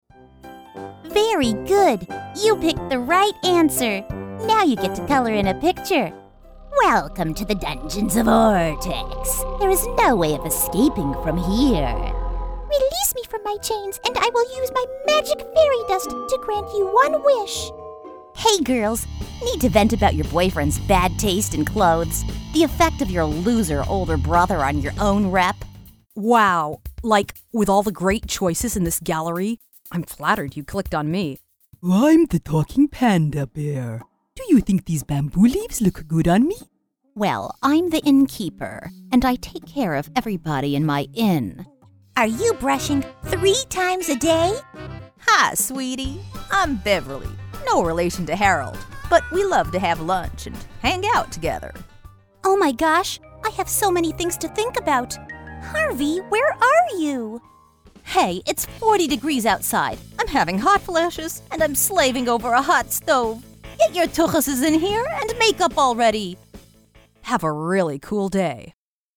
Spécialisée dans l'e-learning et la narration technique, elle offre un doublage professionnel, chaleureux, autoritaire et rapide, idéal pour les projets d'entreprise, éducatifs et axés sur les personnages.
Animation
* Studio traité acoustiquement, pour garantir un son propre et de haute qualité